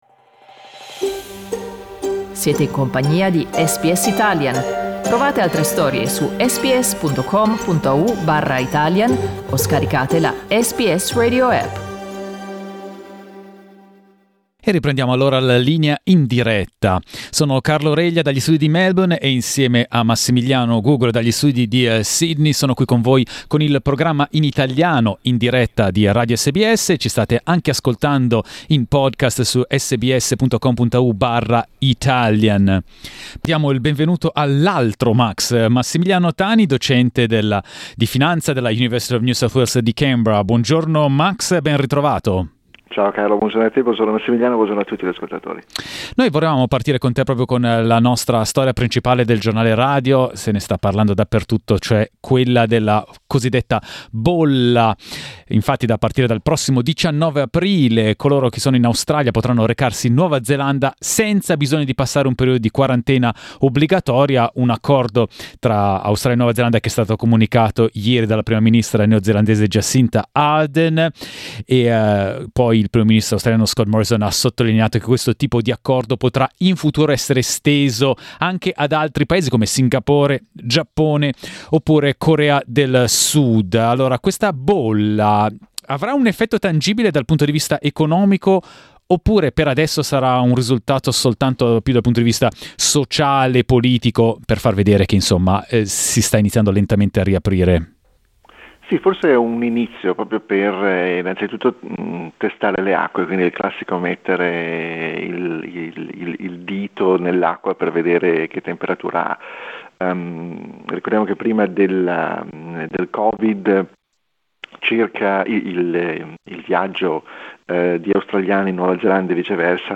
professore di finanza